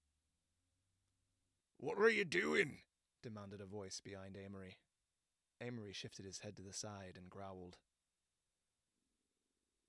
Yes, I hear the truck engine revving in the recordings, but that’s really not of consequence.
Your recording volume is massively too quiet.